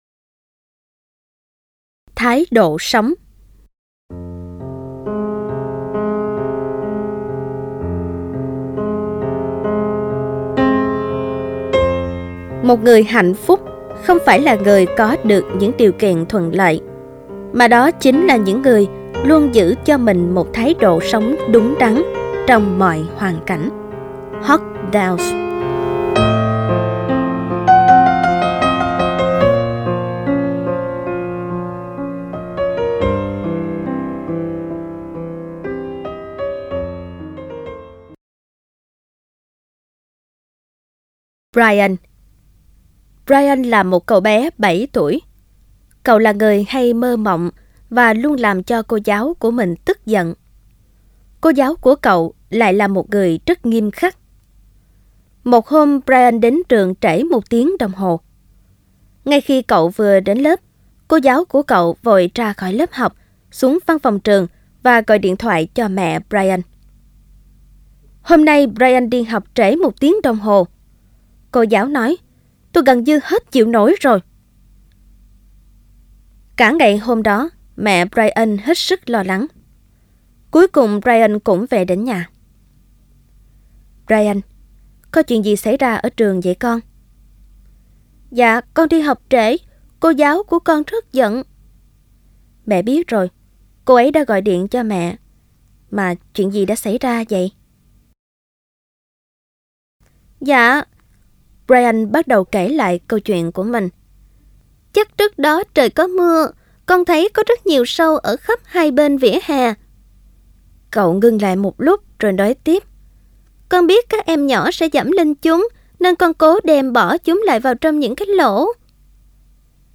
Sách nói Chicken Soup 4 - Chia Sẻ Tâm Hồn Và Quà Tặng Cuộc Sống - Jack Canfield - Sách Nói Online Hay